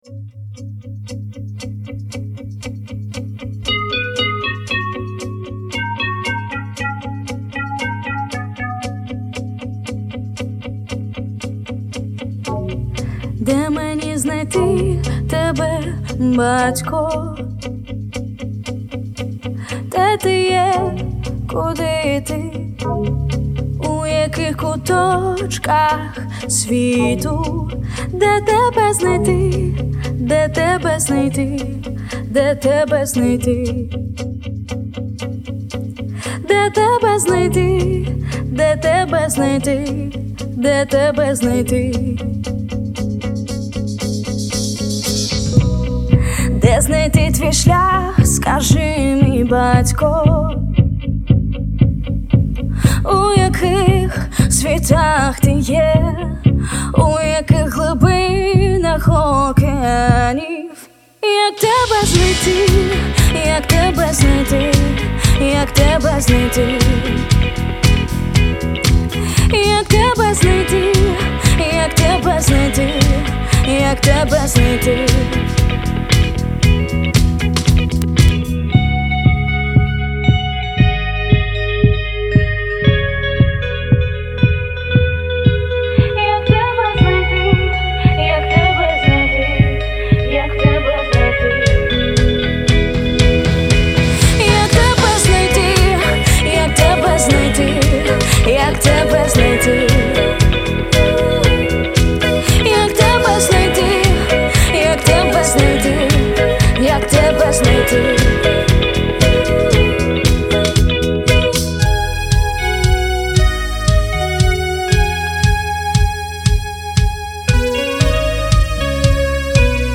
318 просмотров 123 прослушивания 19 скачиваний BPM: 116